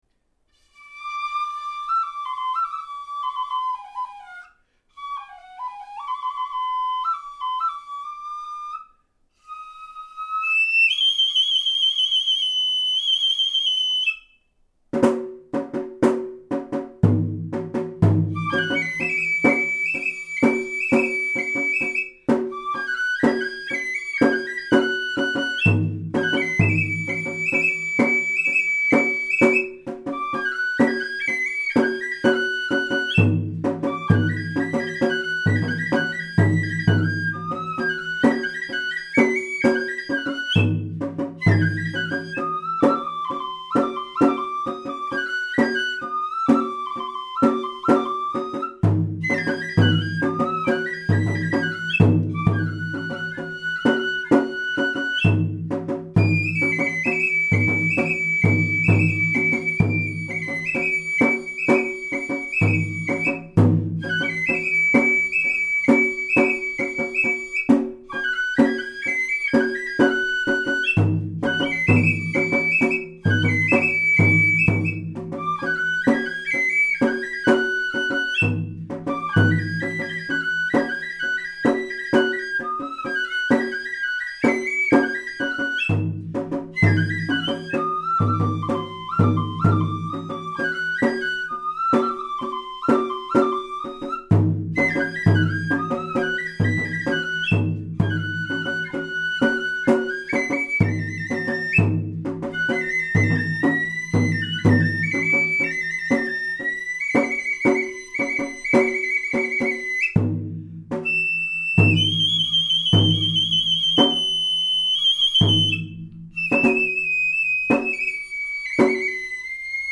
演奏音源：
亀崎の梵天返し（＝知立の下がり葉）とは、一番最初の部分が同じなので、吹き始めが少しまぎらわしいです。
津島下がりは1番が６行で２番が５行であり、どの曲がもともとの曲に近いのかは、よくわからないです。